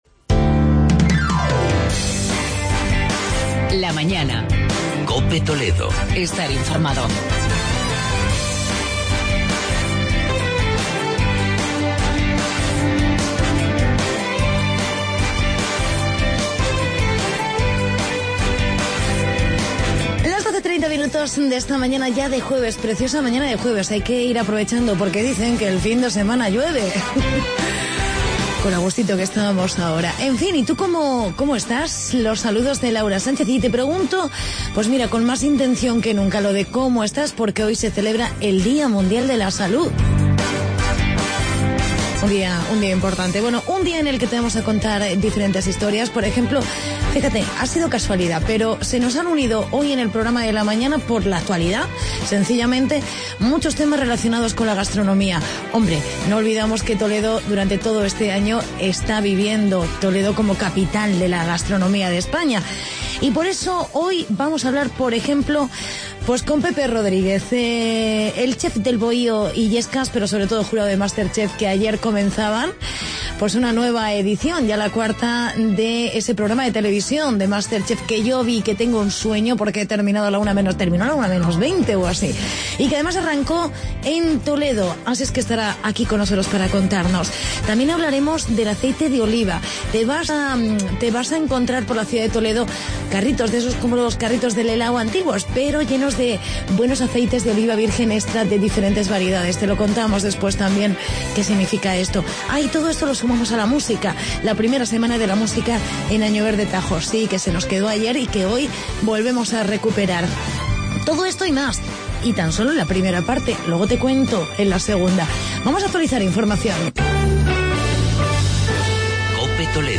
entrevista con Pepe Rodríguez, jurado del programa "Masterchef"